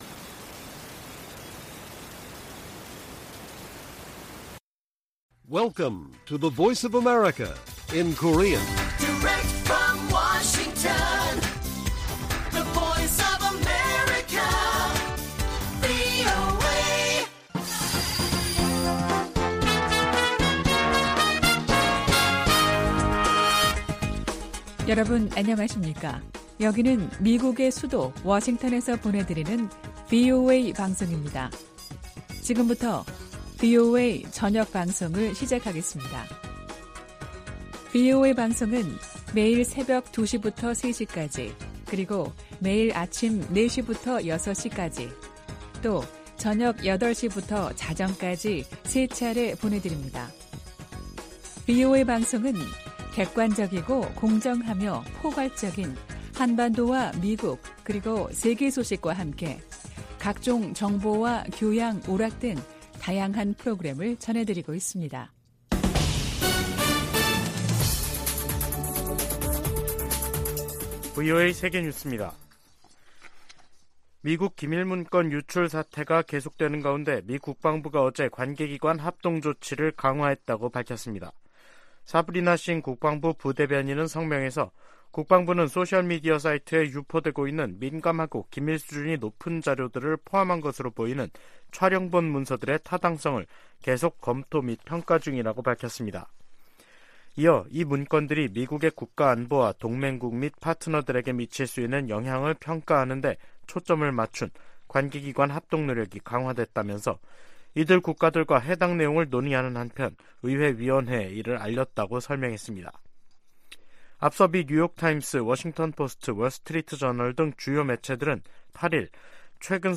VOA 한국어 간판 뉴스 프로그램 '뉴스 투데이', 2023년 4월 10일 1부 방송입니다.